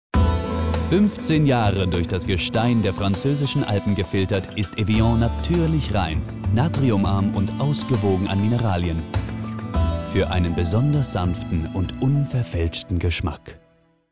sehr variabel
Mittel minus (25-45)
Presentation